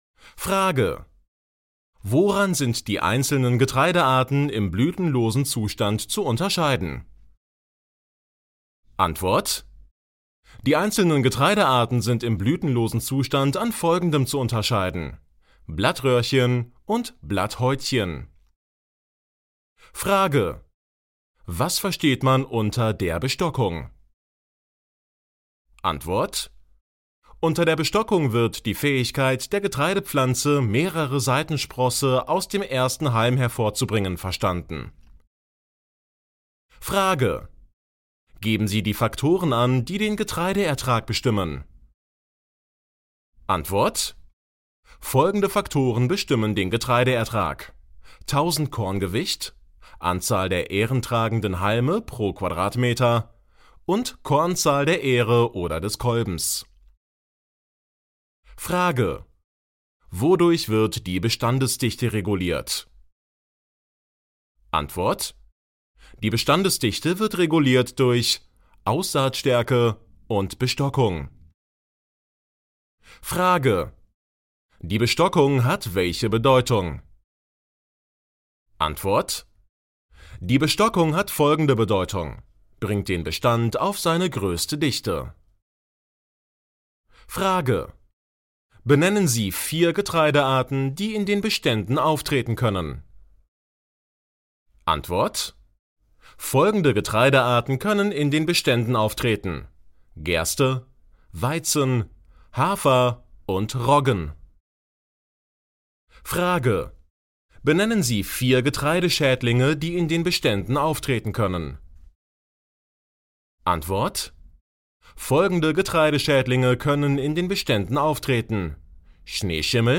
MP3 Hörbuch Landwirt - Download
Hörprobe Landwirt